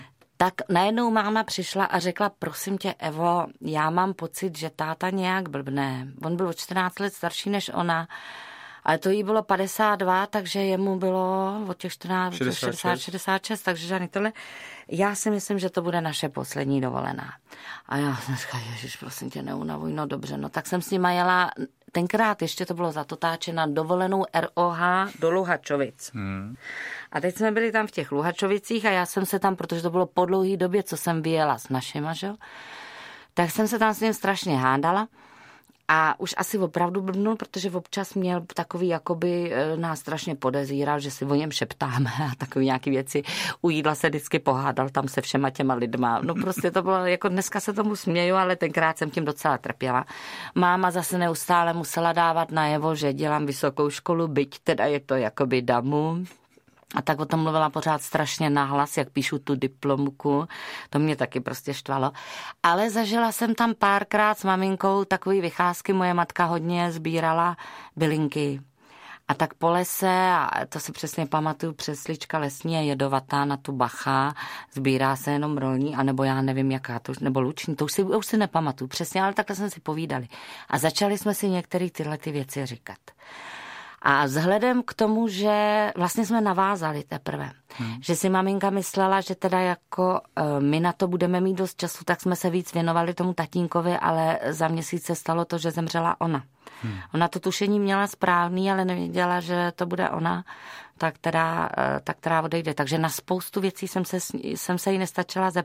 Audio kniha
Ukázka z knihy
• InterpretJaroslav Dušek, Eva Holubová